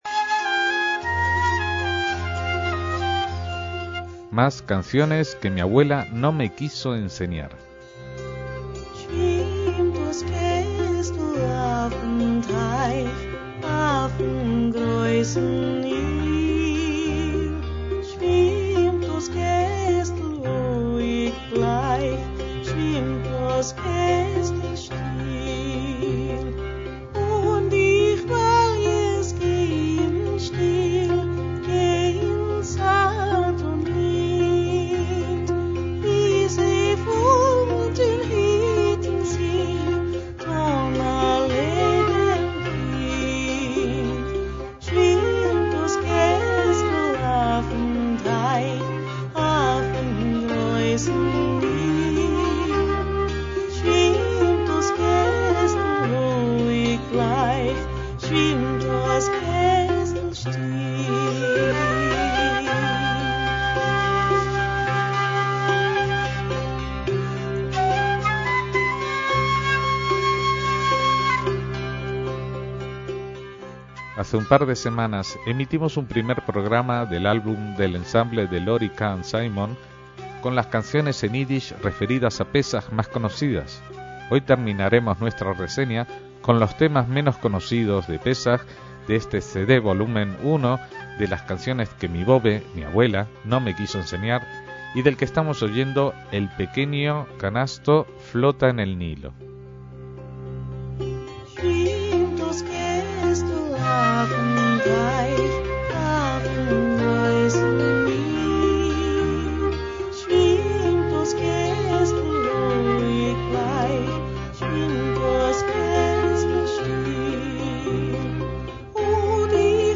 MÚSICA ÍDISH